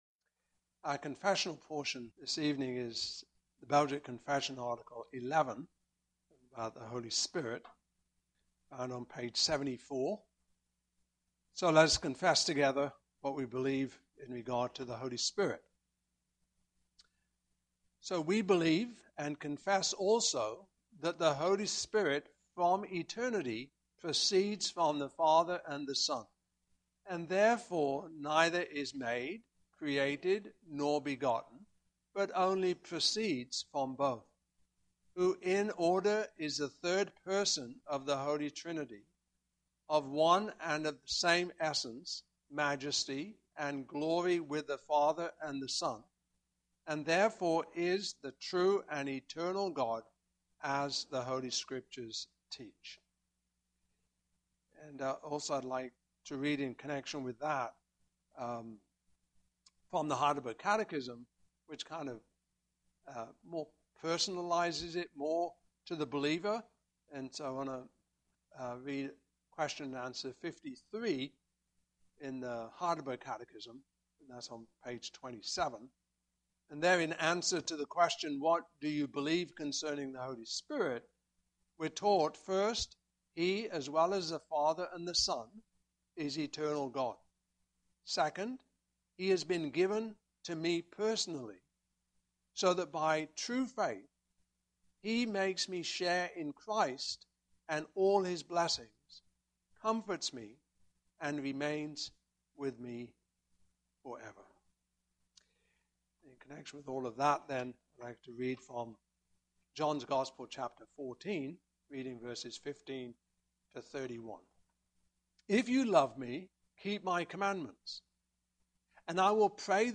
John 14:15-31 Service Type: Evening Service Topics